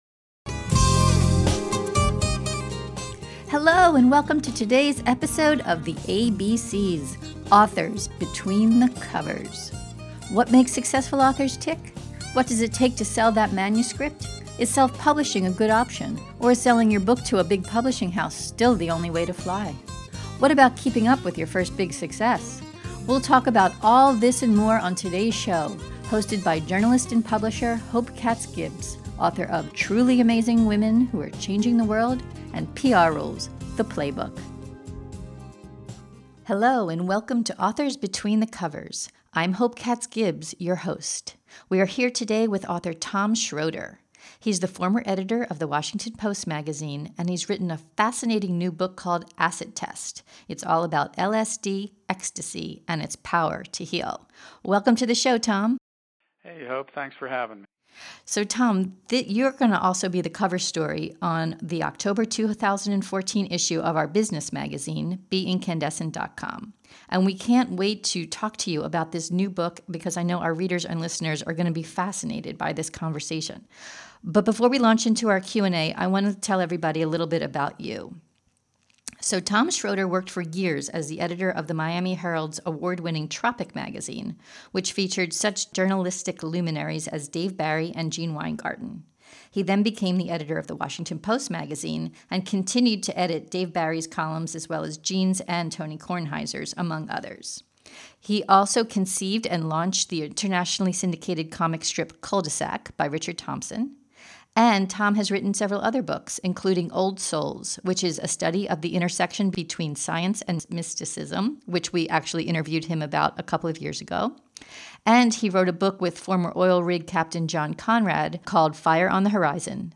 In this podcast interview you’ll learn: